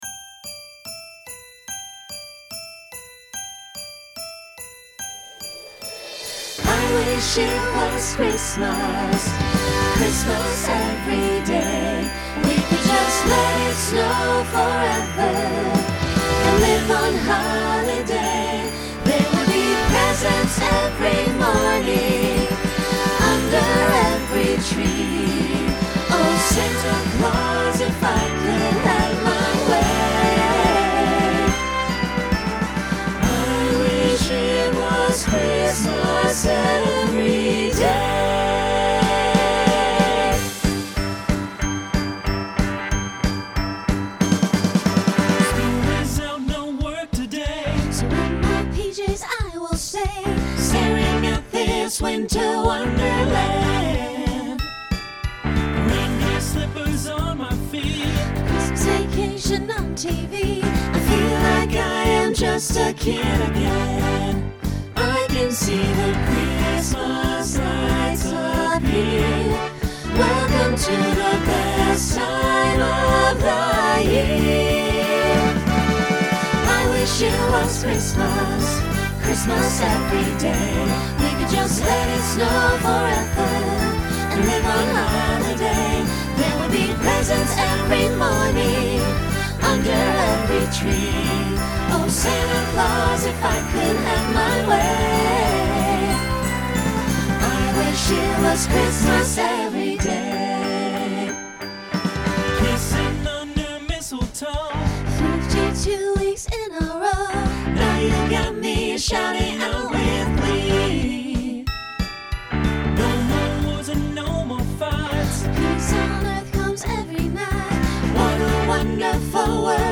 Holiday , Rock Instrumental combo
Opener Voicing SATB